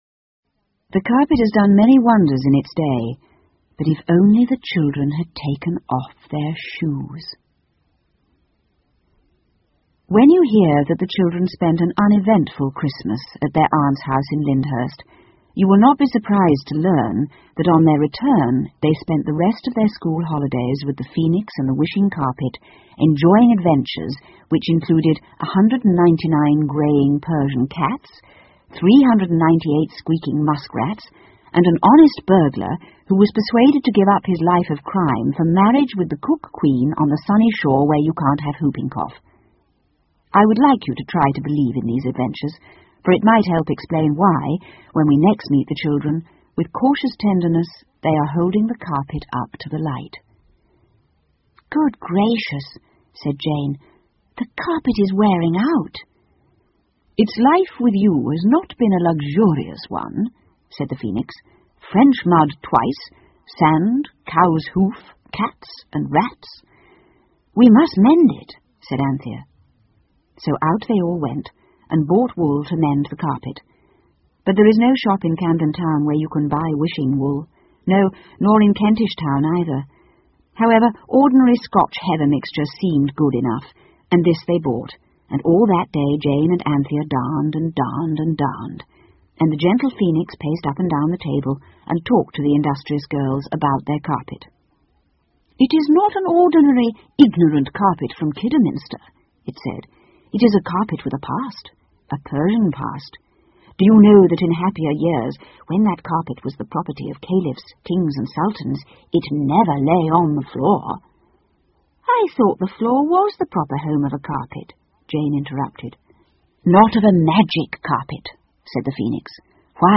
凤凰与魔毯 The Phoenix and the Carpet 儿童英语广播剧 11 听力文件下载—在线英语听力室